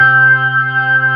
55o-org09-C3.wav